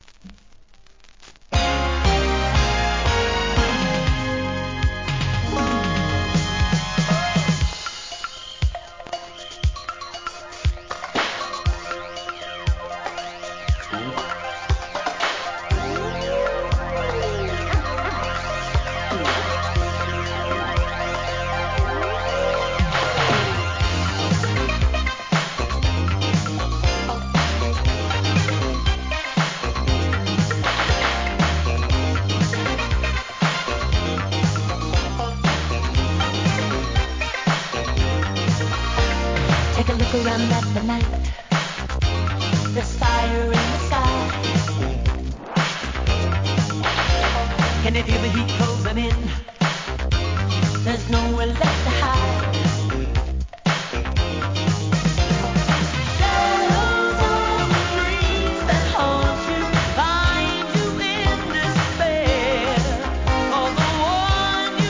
SOUL/FUNK/etc...
1986年バラード!